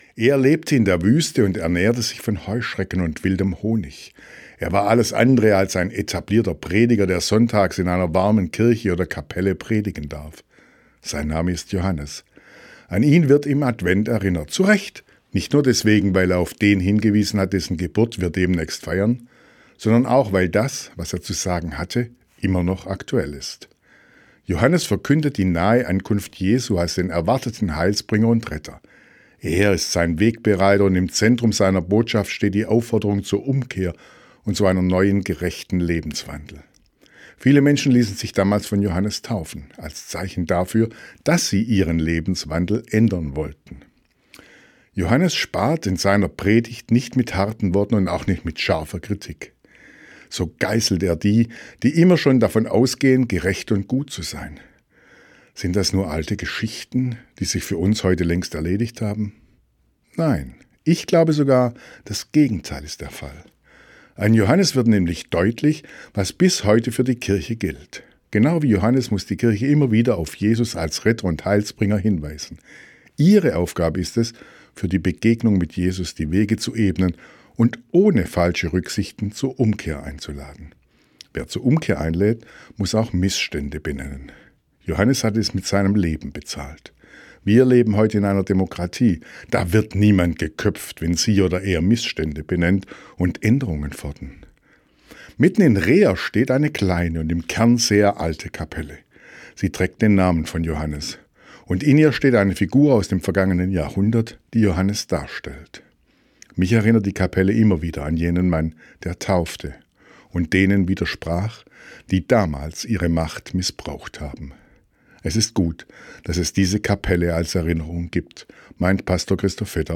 Radioandacht vom 17. Dezember